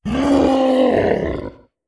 Monster Roars
06. Battle Roar.wav